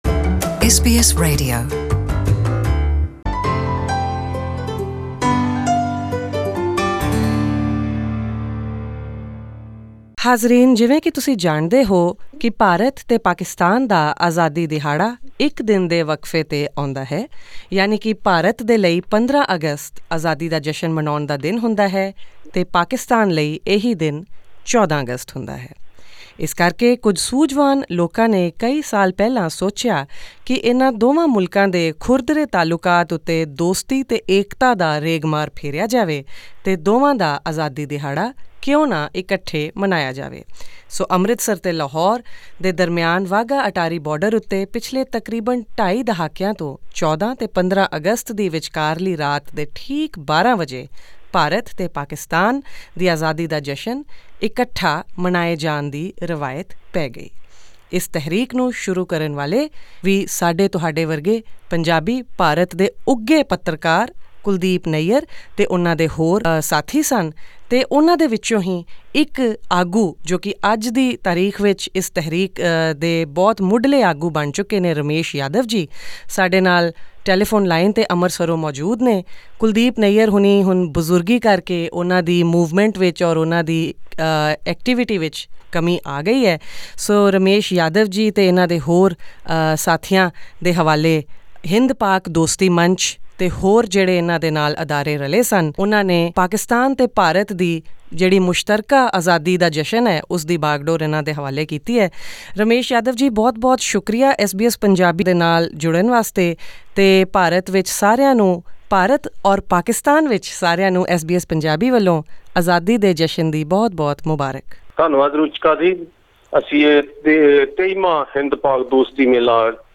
He mentioned how it was different this year because some participants from Pakistan were not granted visas for India and vice-versa, ironically, to support a peace-promotion event between the two countries which are currently at loggerheads with other more than ever before.